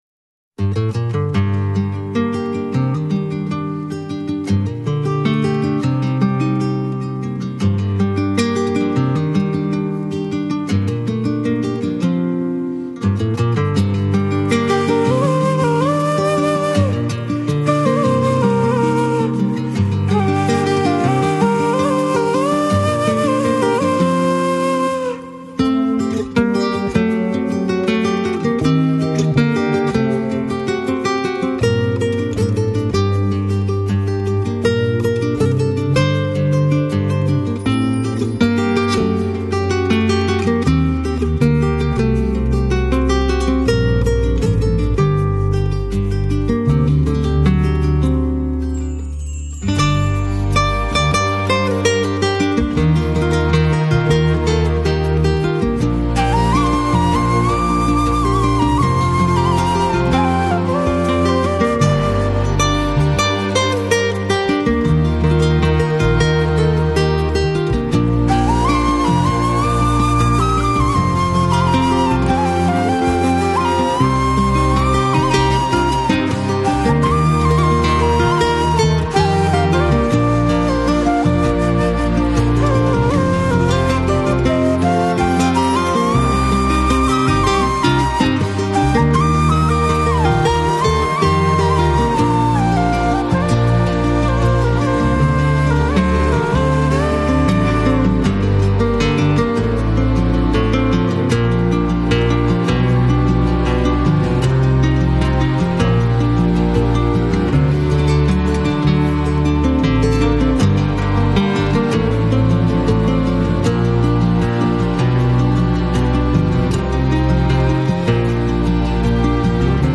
Жанр: Instrumental, New Age